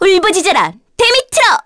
Ophelia-Vox_Skill4_kr.wav